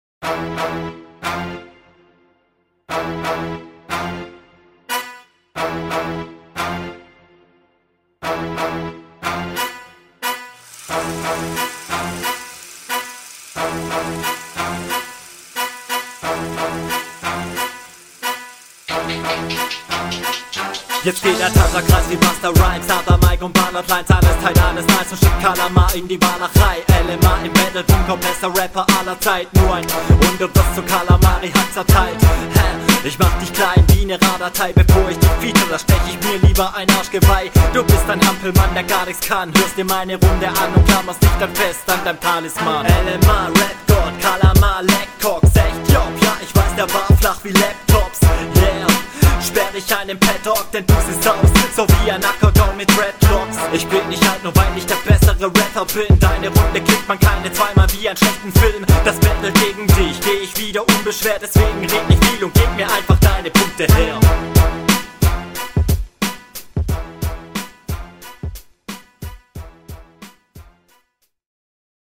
Der Beat ist bis auf die Drums eine Katastrophe.
Stimme ist etwas zu sehr im Hintergrund.